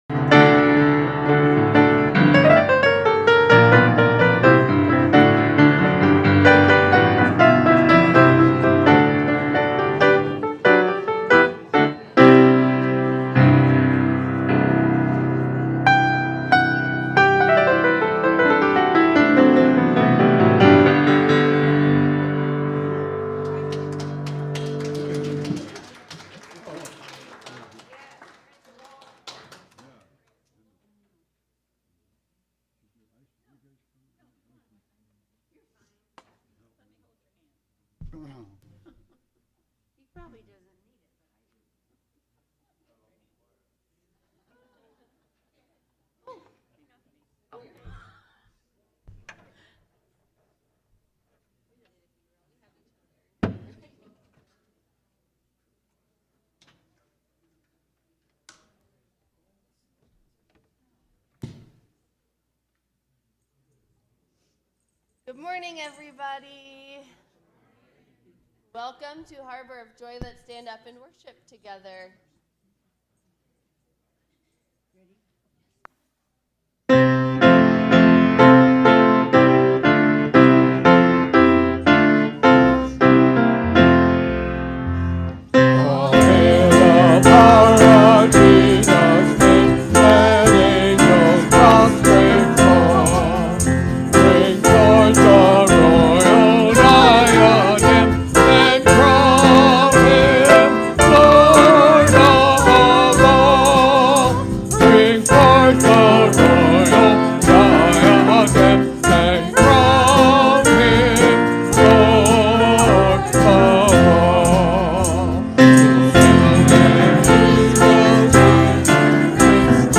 Worship-April-6-2025-Voice-Only.mp3